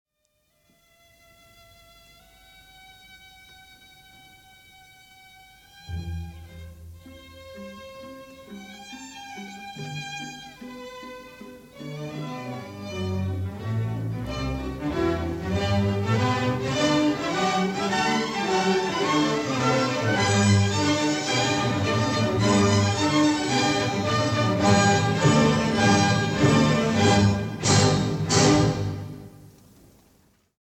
Znakomite, mocne i wyraziste jest zakończenie: